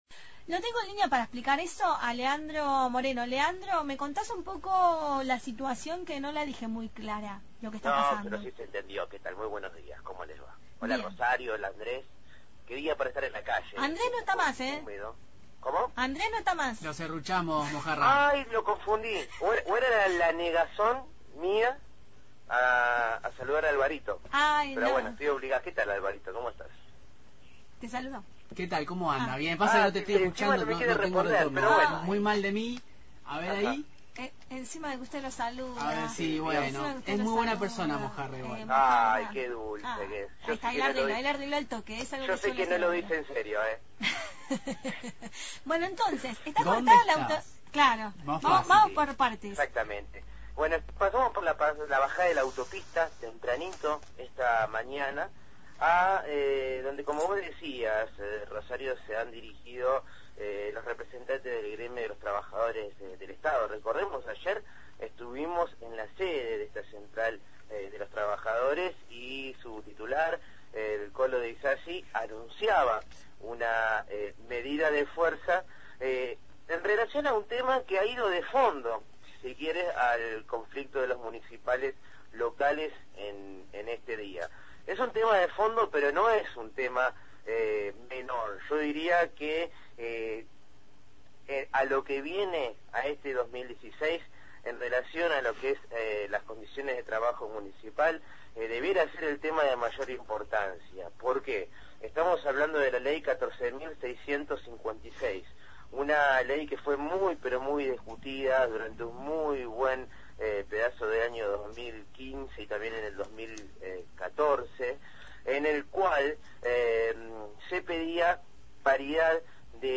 MÓVIL/ Movilización de ATE – Radio Universidad